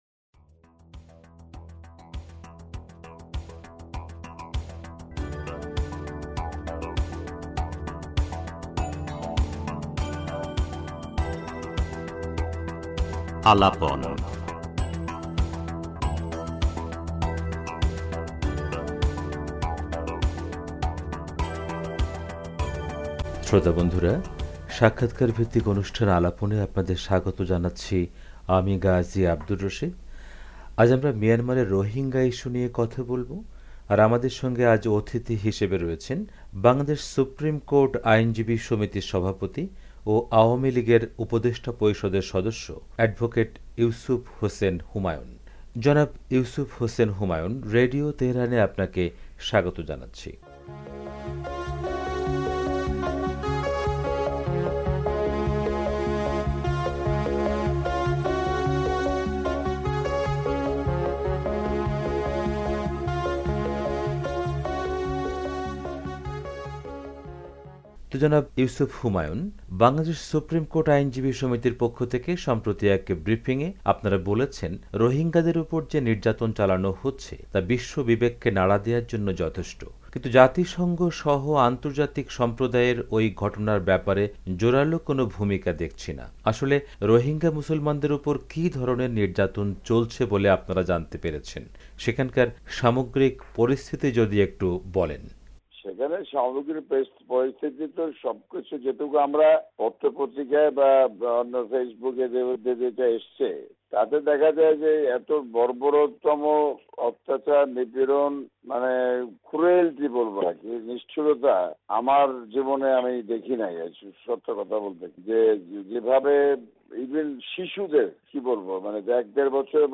মিয়ানমারের রোহিঙ্গা ইস্যু নিয়ে রেডিও তেহরানকে এক্সক্লুসিভ সাক্ষাৎকার দিয়েছেন বাংলাদেশ সুপ্রিম কোর্ট বার এসোসিয়েশনের সভাপতি ও আওয়ামী লীগের উপদেষ্টা পরিষদের...